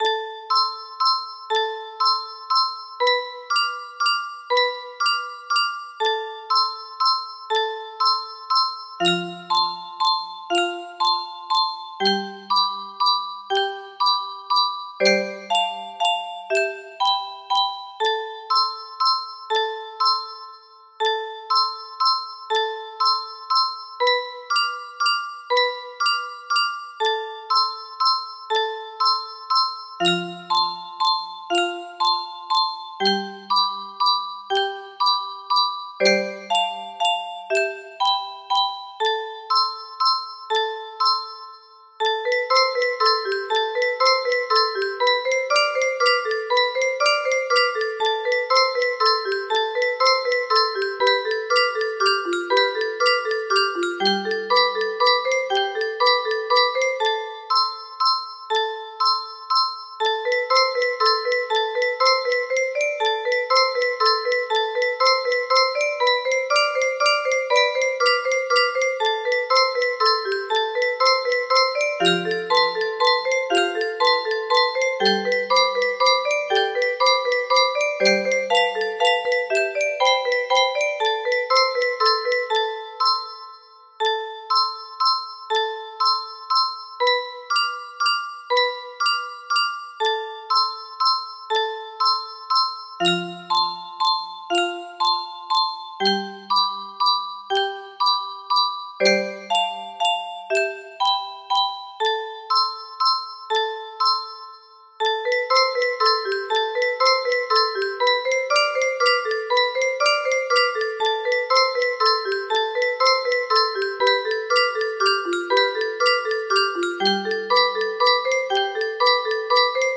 Grand Illusions 30 (F scale)
Mein selbstgeschriebener Walzer. nicht perfekt aber ganz Ok.